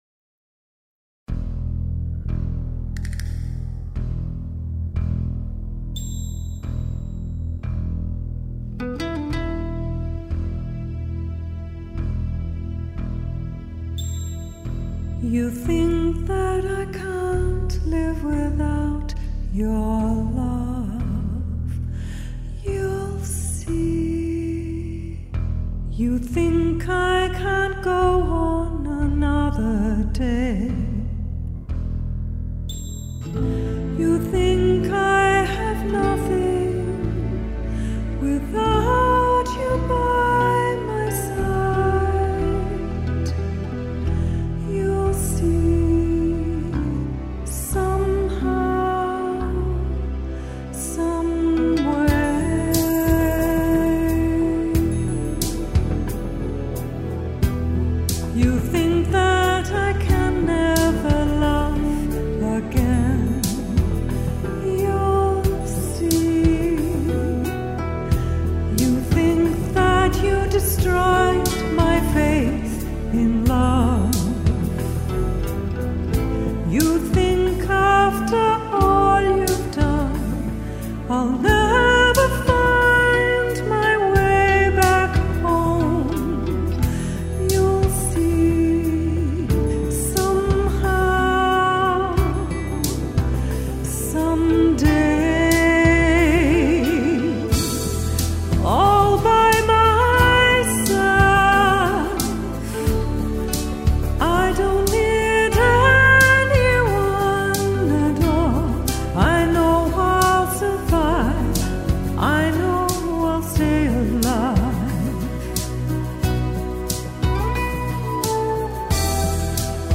Demo tracks recorded & mixed in Vancouver, BC Canada at:
Studio Recorded November, 2017